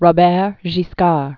(rô-bĕr gē-skär) 1015?-1085.